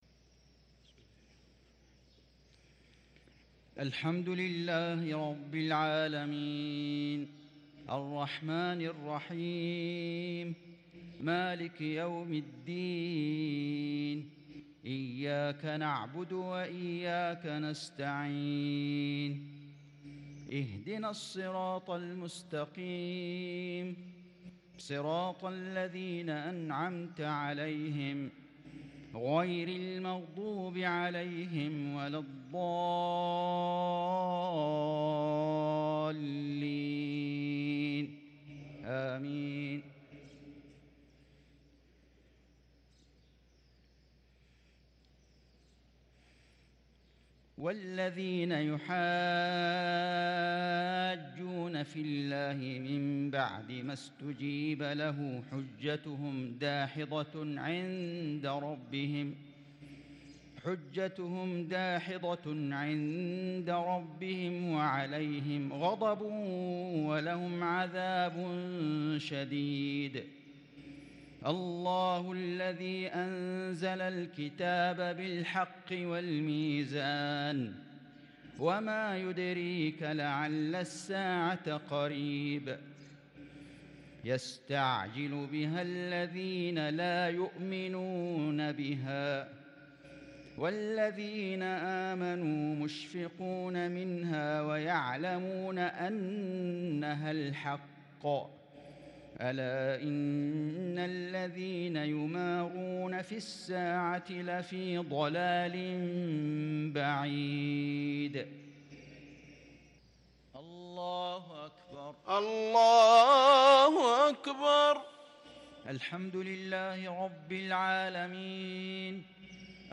مغرب ٤-٨ -١٤٤٣هـ سورة الشورى | Maghrib prayer from suarh ash-Shura 7-3-2022 > 1443 🕋 > الفروض - تلاوات الحرمين